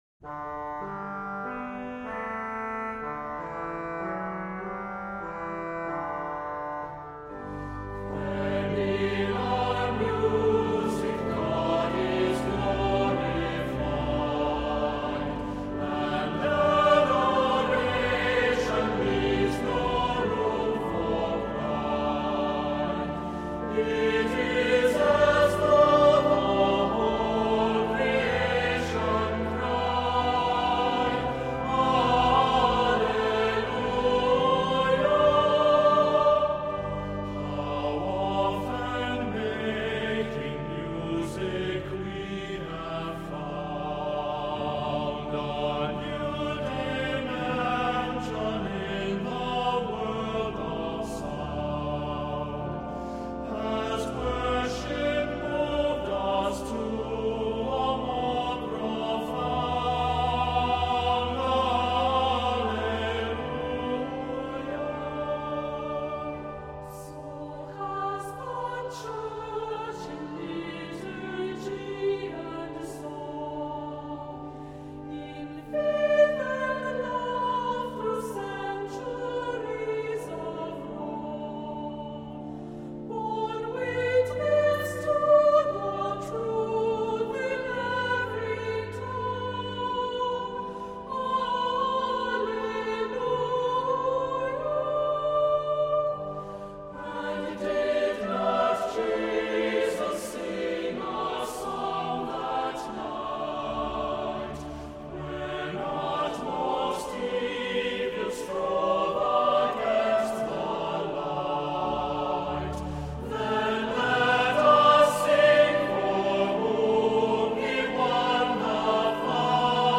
Accompaniment:      Organ
Music Category:      Christian